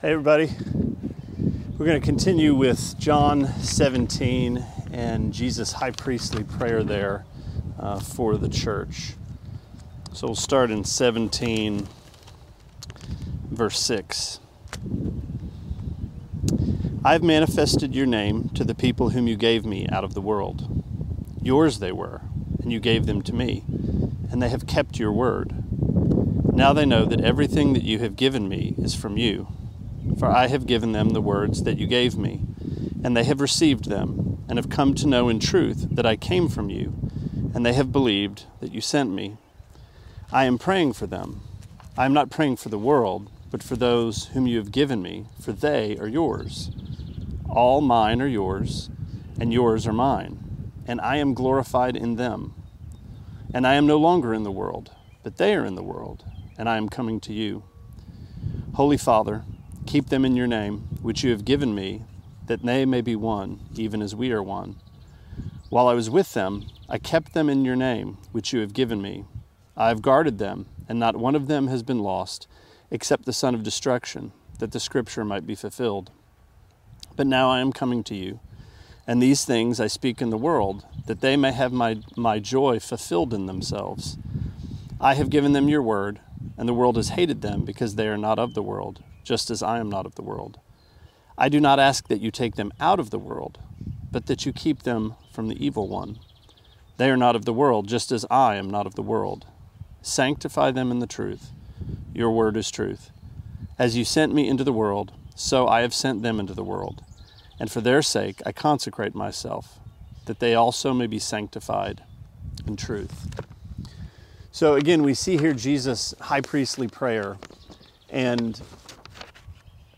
Sermonette 6/1: John 17:6-19: More Than Bread